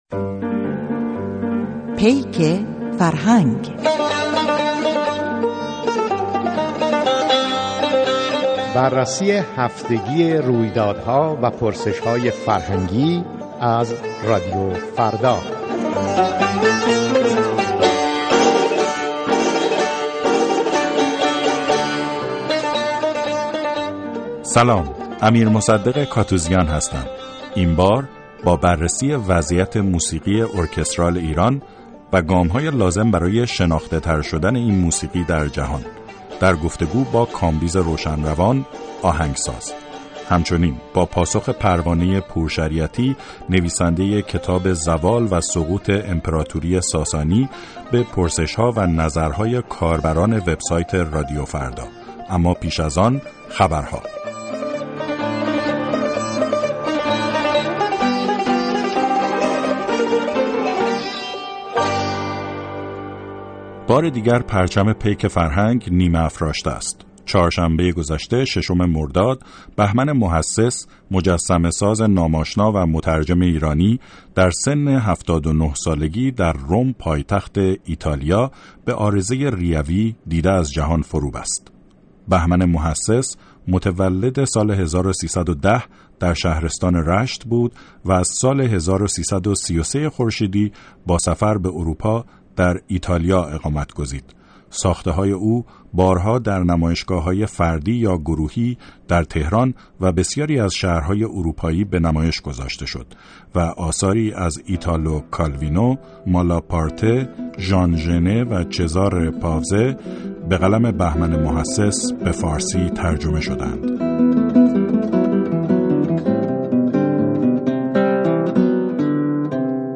نهمین برنامه هفتگی پیک فرهنگ/ مصاحبه